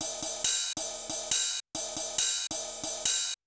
JAZZ RIDE+-L.wav